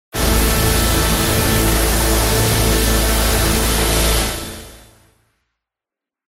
Audio / SFX / Abilities